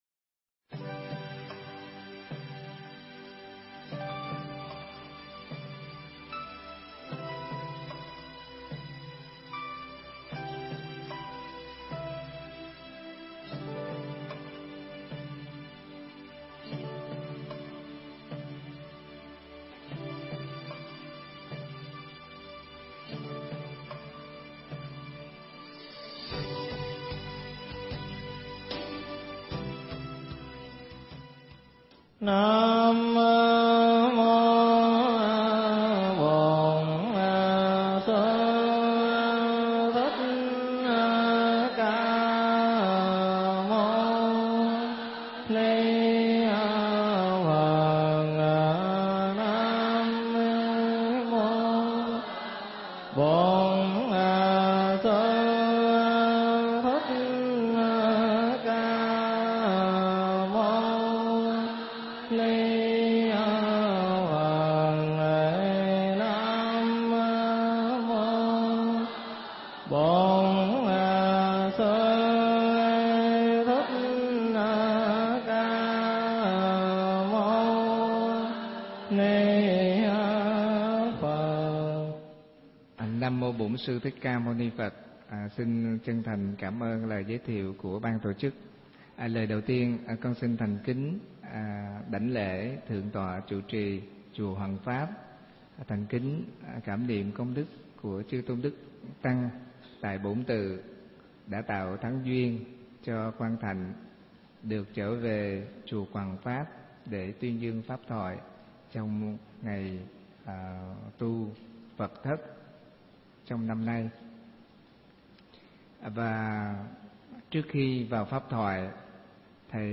Nghe Mp3 thuyết pháp Chữ Tu Trong Đạo Phật